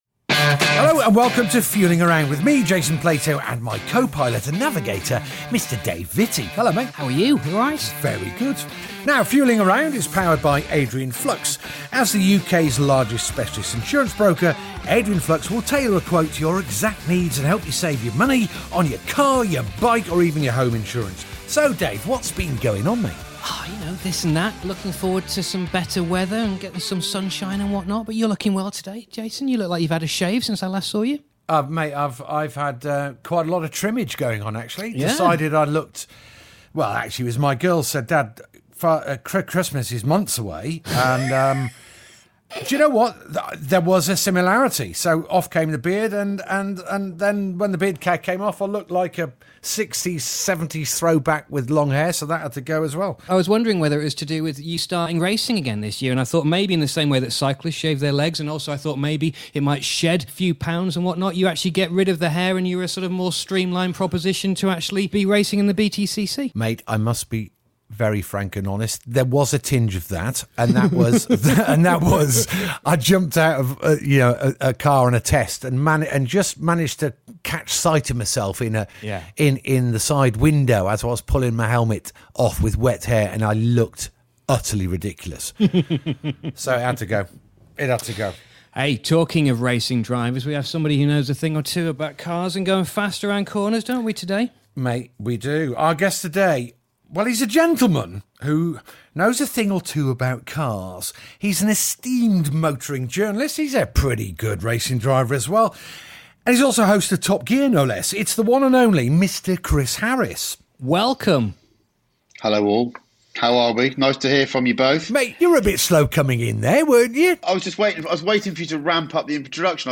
This episode was recorded remotely.